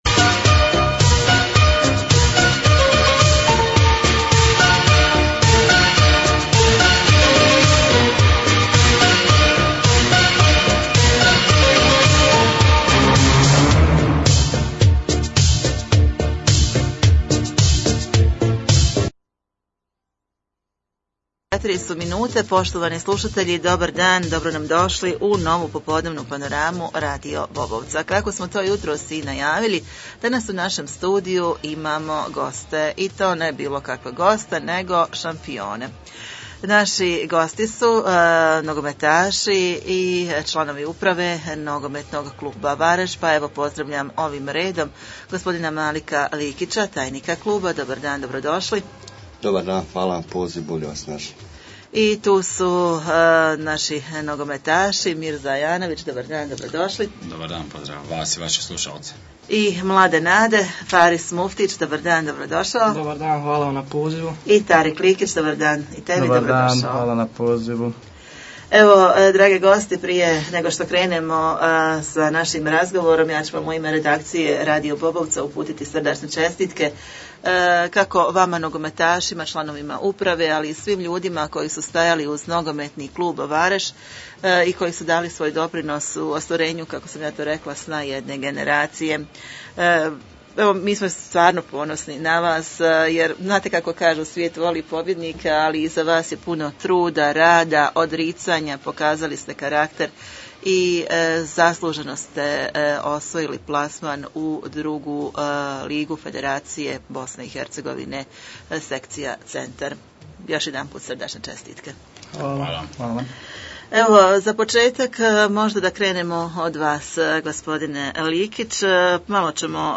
Razgovor s članovima NK Vareš nakon osvajanja prvenstva ZDK